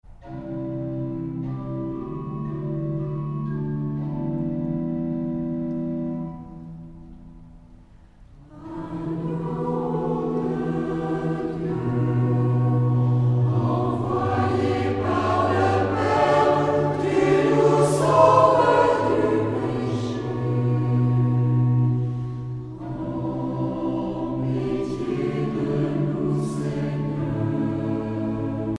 dévotion, religion